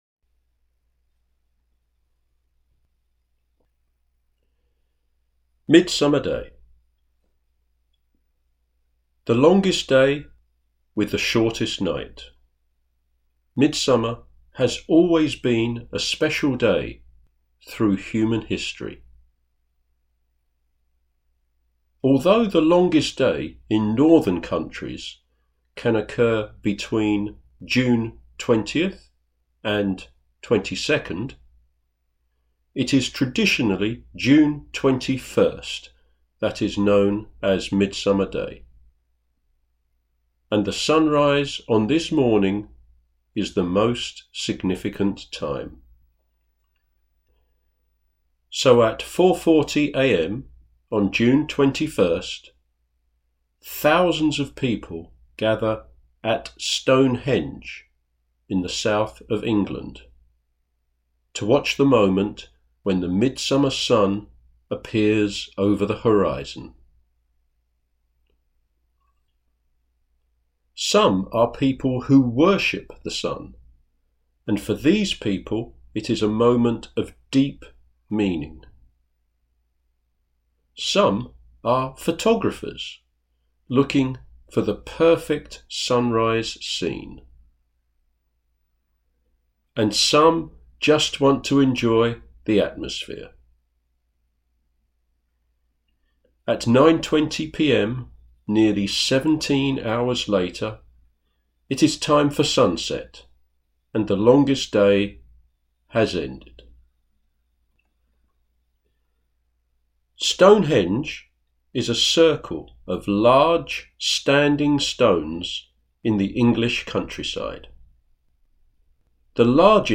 NARRATION
Midsummer Day audio narration